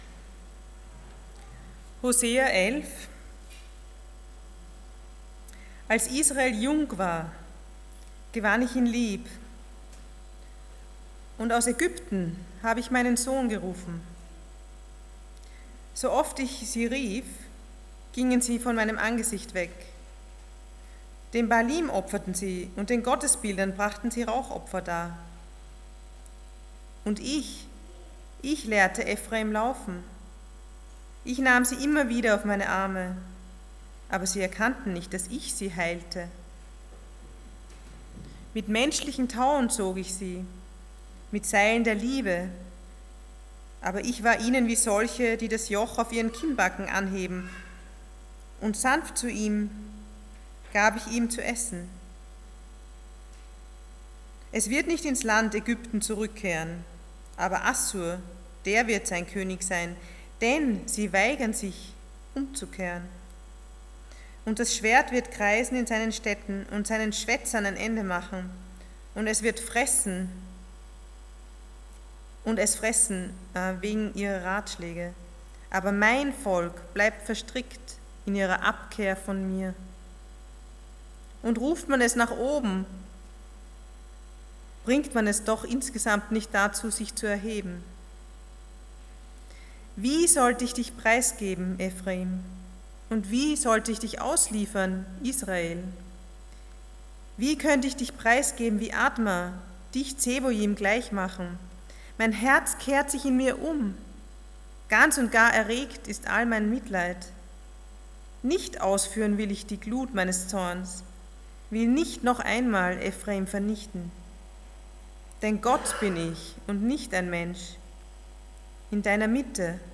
Passage: Hosea 11:1-11 Dienstart: Sonntag Morgen